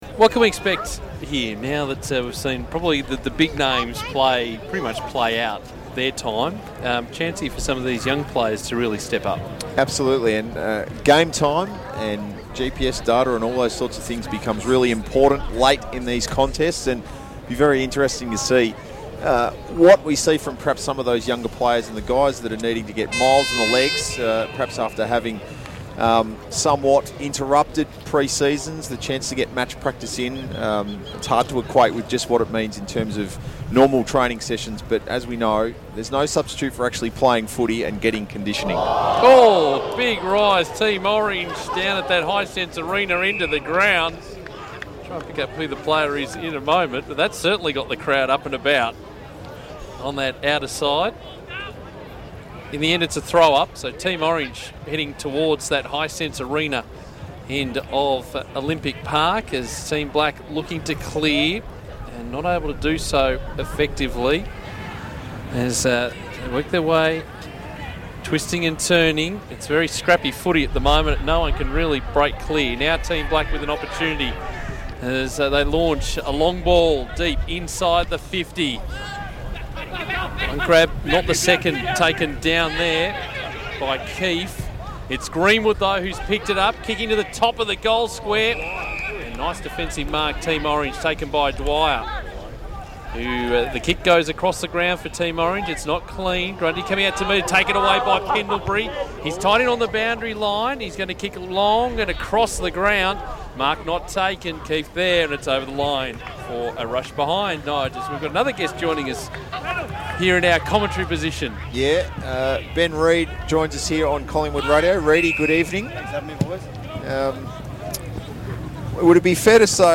Listen to Collingwood Radio's commentary of the Magpies' first intra-club practice match of 2015 on Thursday 19 February.